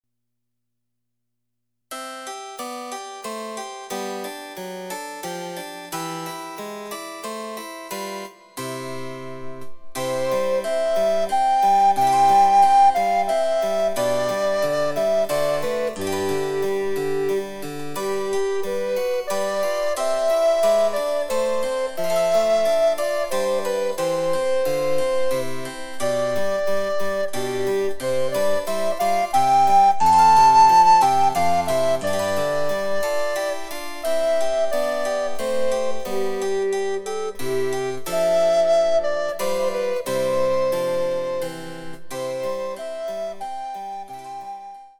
・リコーダー演奏例
デジタルサンプリング音源使用